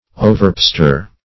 Search Result for " overpester" : The Collaborative International Dictionary of English v.0.48: Overpester \O`ver*pes"ter\, v. t. To pester exceedingly or excessively.